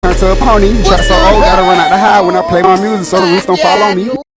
Goofy Ahh Weird Sound Effect Download: Instant Soundboard Button
Pranks Soundboard1,044 views